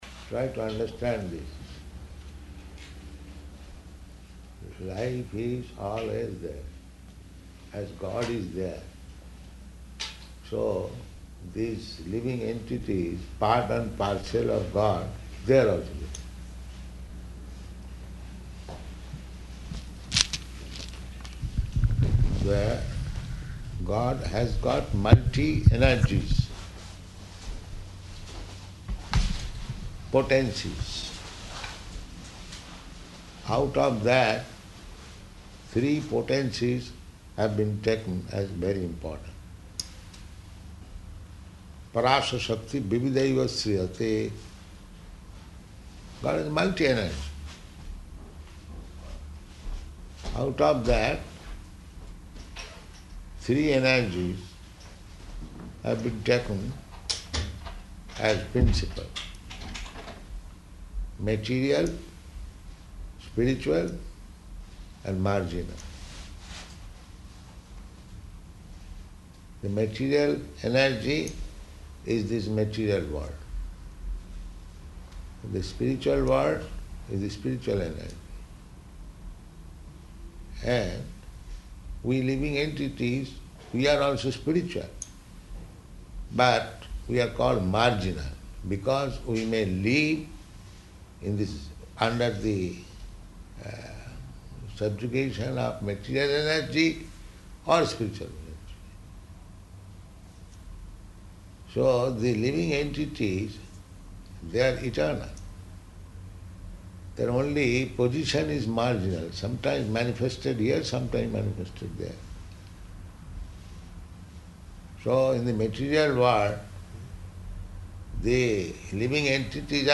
Type: Conversation
Location: Atlanta